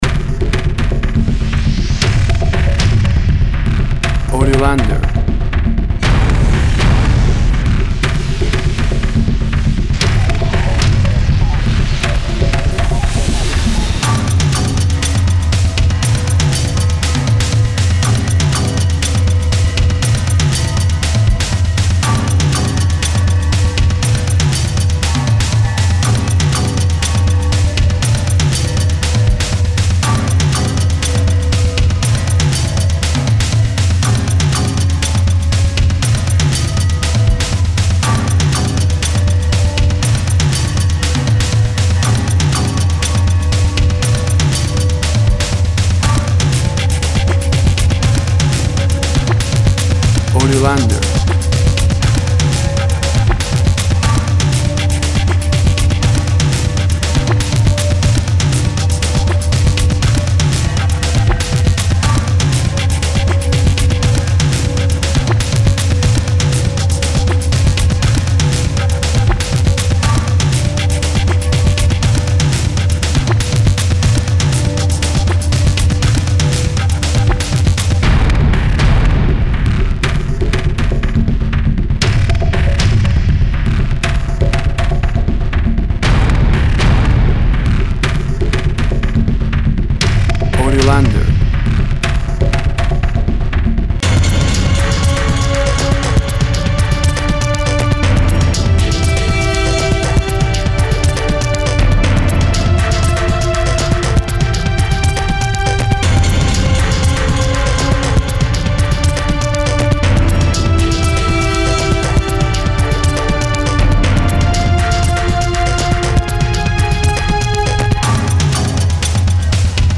Very energetic, a percussive track for intense environments.
Tempo (BPM) 130